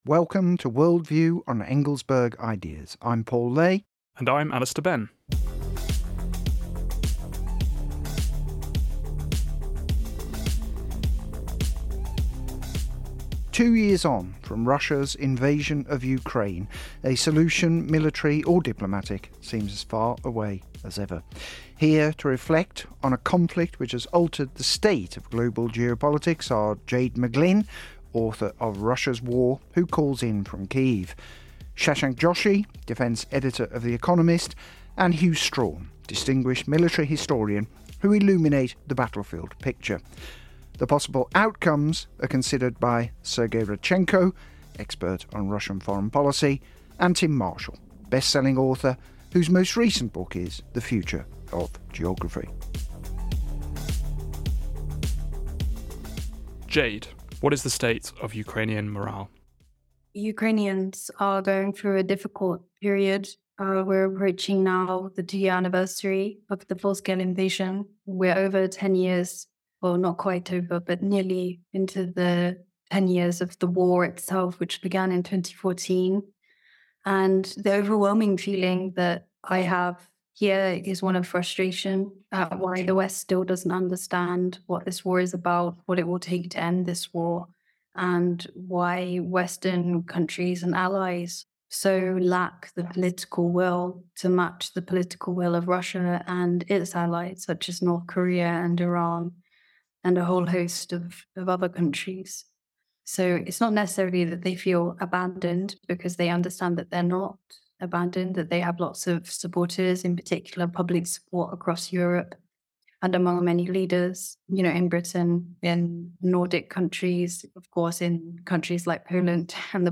On Worldview, leading historians and commentators reflect on a conflict that has altered the state of global geopolitics.